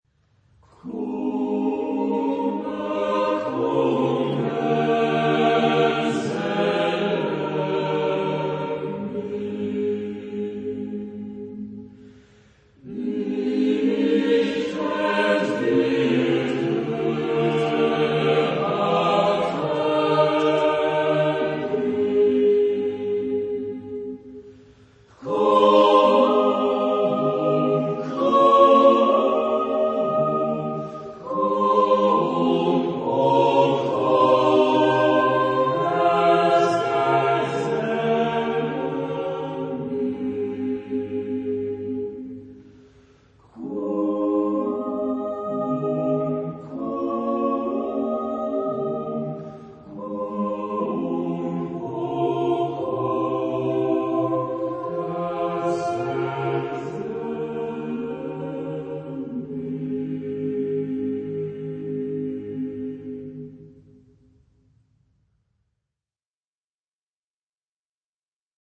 Genre-Stil-Form: Liedsatz ; Volkslied ; weltlich
Chorgattung: SATB  (4 gemischter Chor Stimmen )
Tonart(en): ionisch